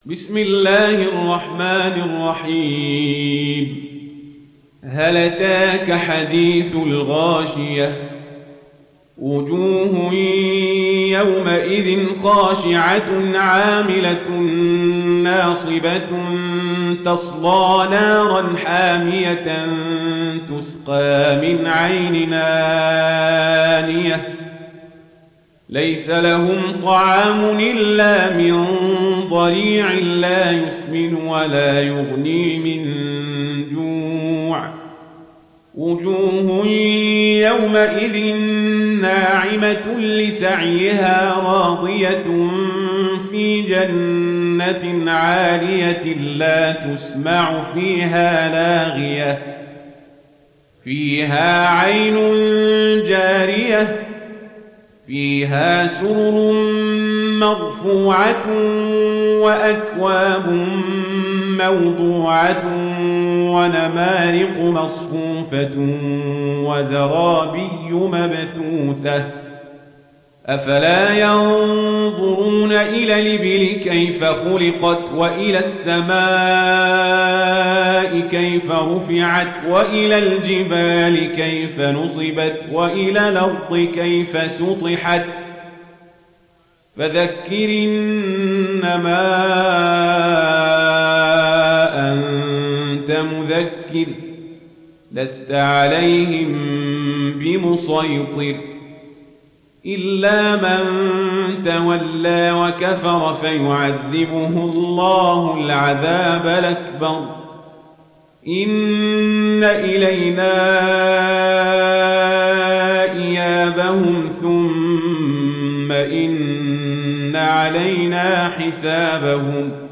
تحميل سورة الغاشية mp3 عمر القزابري (رواية ورش)